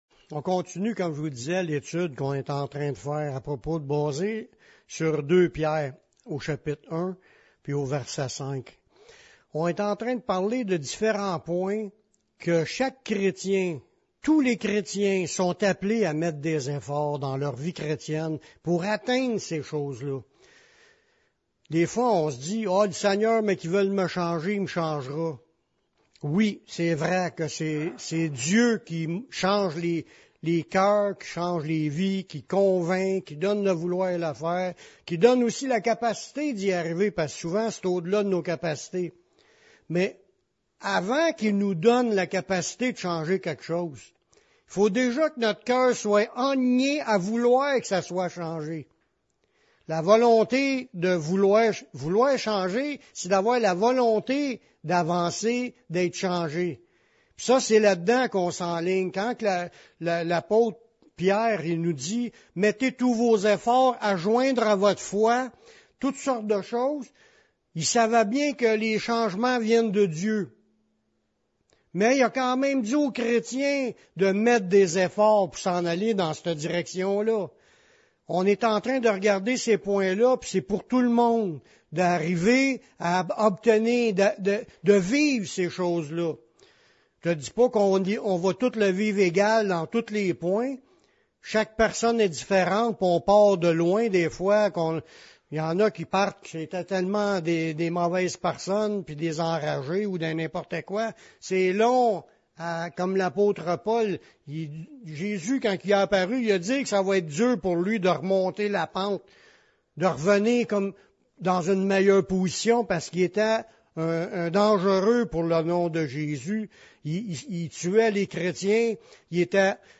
La pâque biblique, une fête éternelle.. Émission Radio Évangélique du 19 avril 2024.